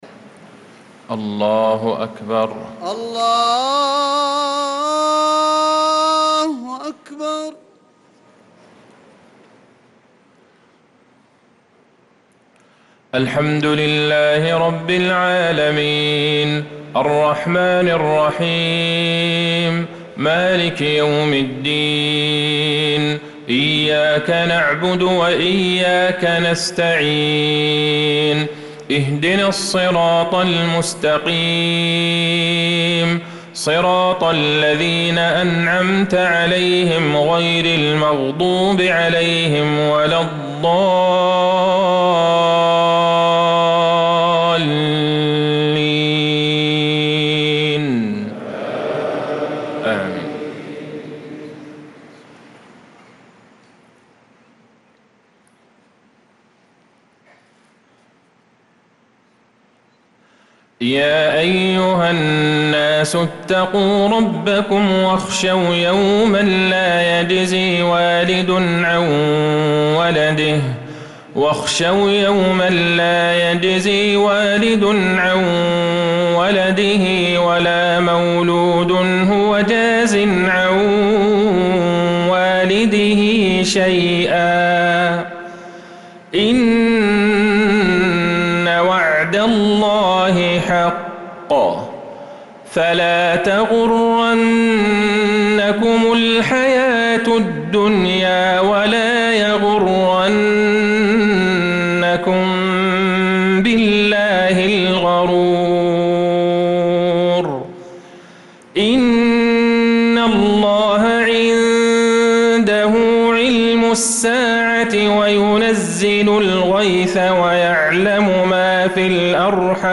صلاة المغرب للقارئ عبدالله البعيجان 22 ذو الحجة 1445 هـ
تِلَاوَات الْحَرَمَيْن .